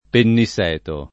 [ penni S$ to ]